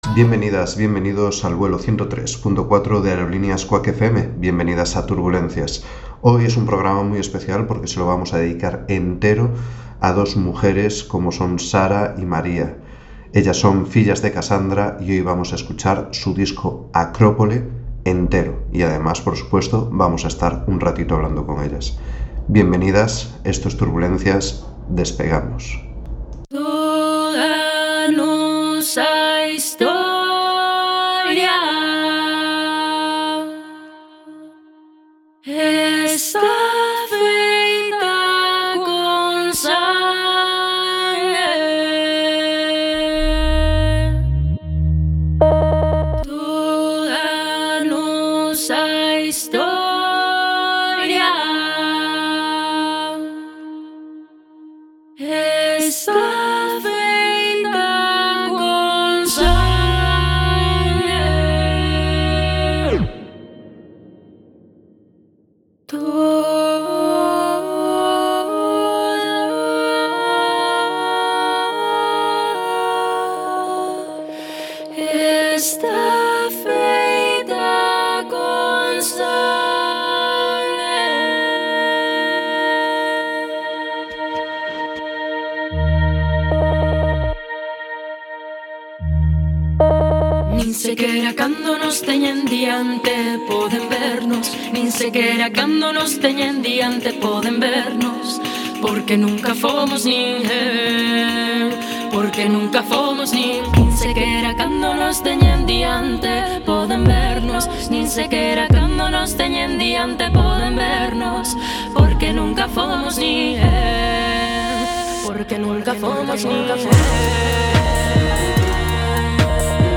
Turbulencias es un Magazine musical que abarca la actualidad musical a través de noticias, entrevistas, agenda y monográficos además de otras muchas secciones. Queremos que descubras música a nuestro lado.